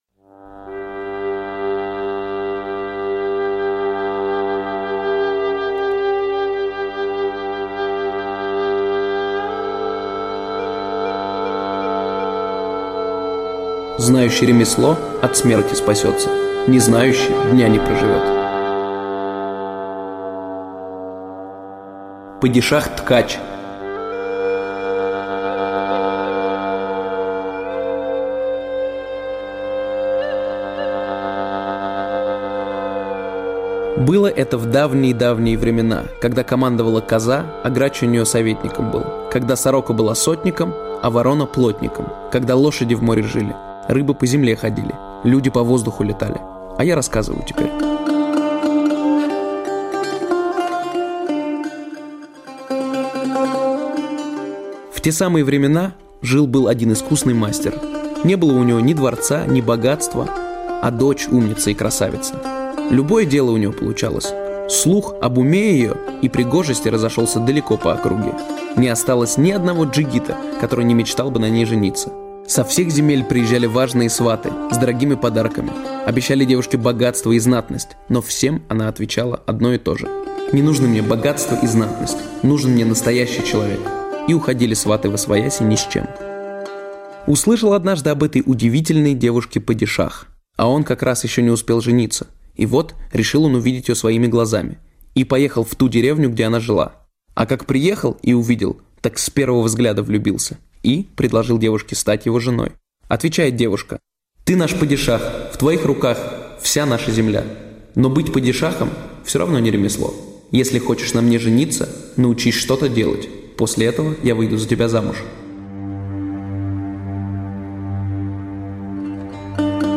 Падишах-ткач - татарская аудиосказка - слушать